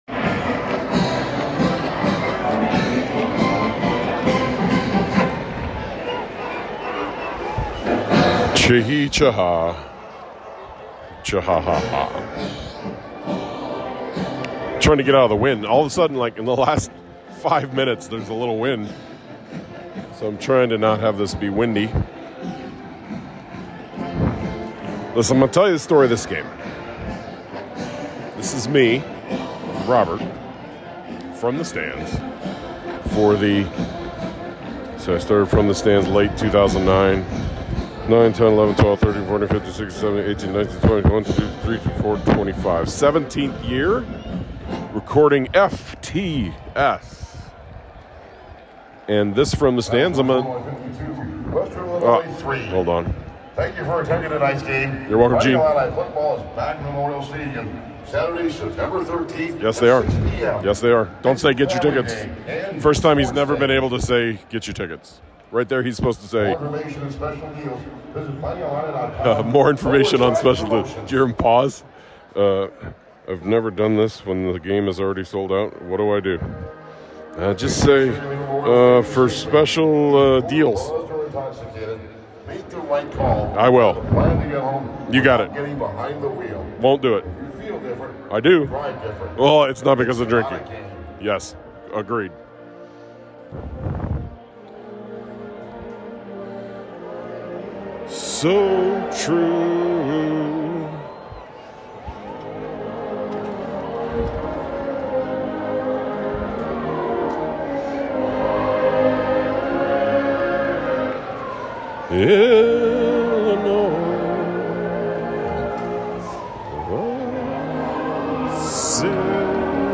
The decision was made to make Club Memorial Stadium even louder this year, so...
Sixteen minutes from the stands after the Western Illinois game.